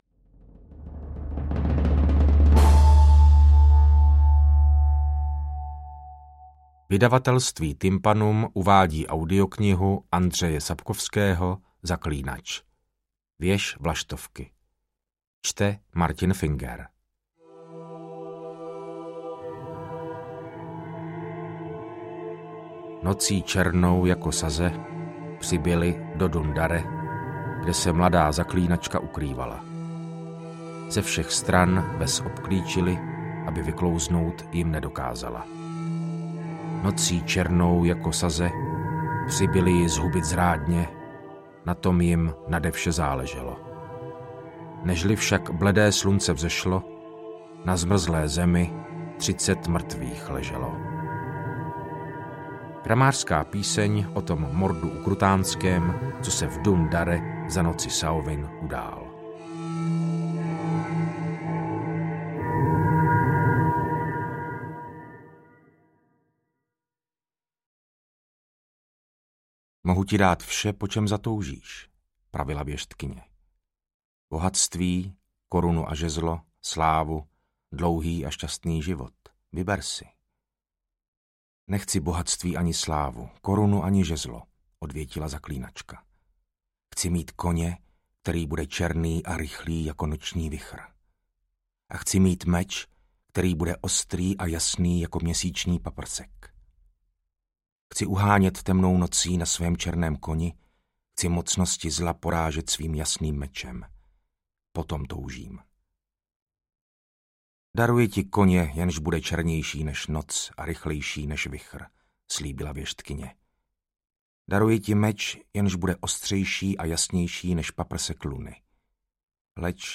Interpret:  Martin Finger
V audioverzii je, podobne ako ostatné diely, bravúrne nahovorená p. Martinom Fingerom.
AudioKniha ke stažení, 91 x mp3, délka 17 hod. 19 min., velikost 909,0 MB, česky